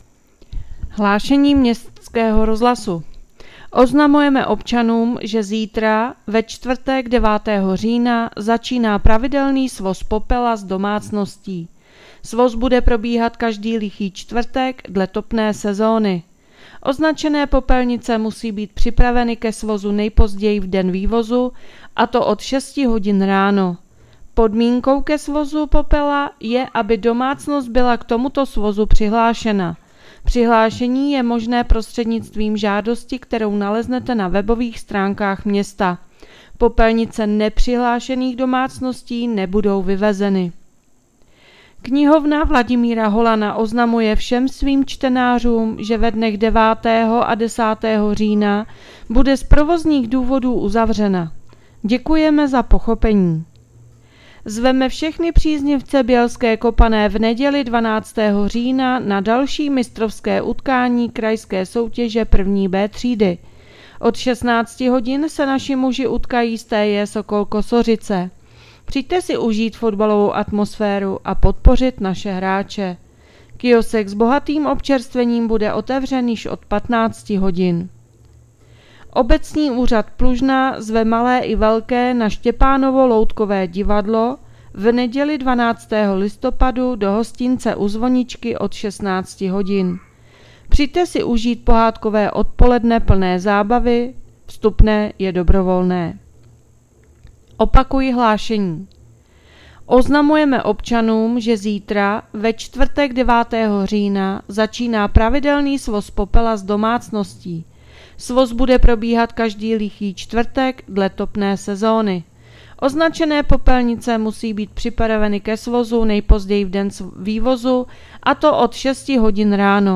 Hlášení městského rozhlasu 8.10.2025